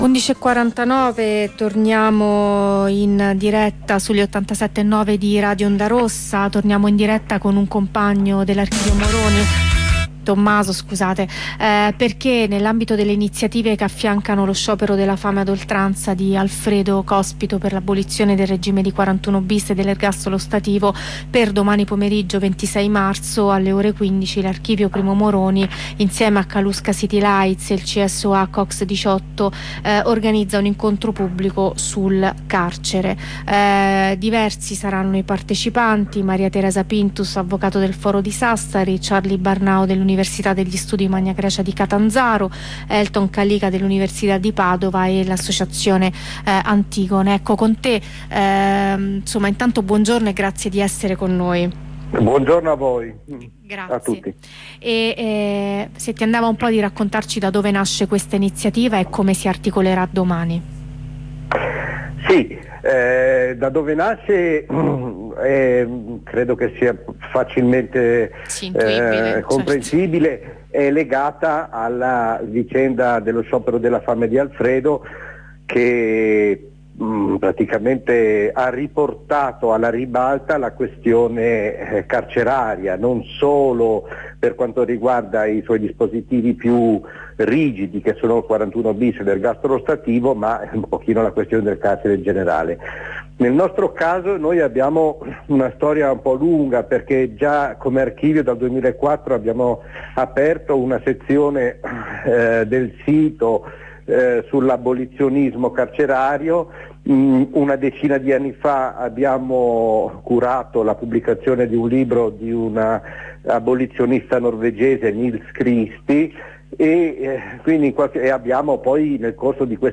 Corrispondenza con il presidio solidale